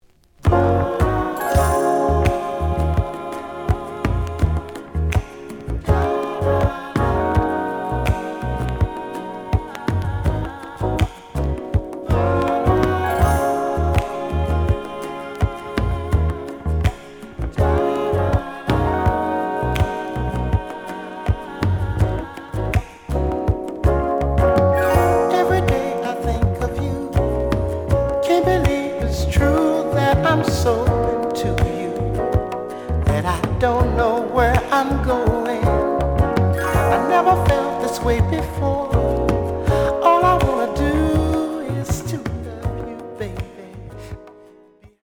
The audio sample is recorded from the actual item.
●Format: 7 inch
●Genre: Soul, 80's / 90's Soul